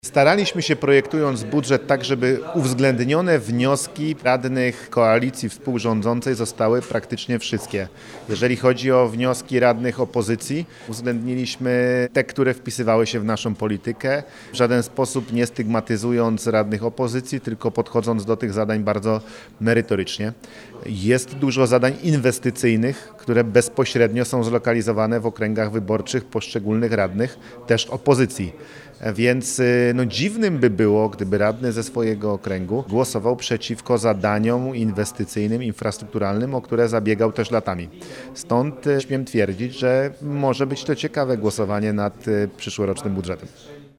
Podczas konferencji prezentującej założenia budżetowe członkowie zarządu województwa podkreślili, że przyszłoroczny budżet będzie większy od tegorocznego o 800 mln zł.
Nie stygmatyzowaliśmy radnych opozycji, a do zadań podeszliśmy merytorycznie – dodaje marszałek Gancarz.